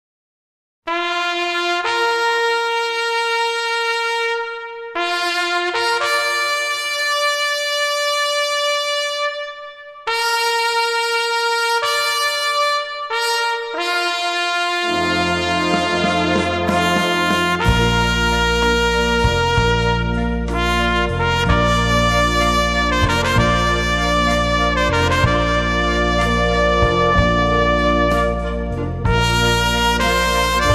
traditional Bavarian folk music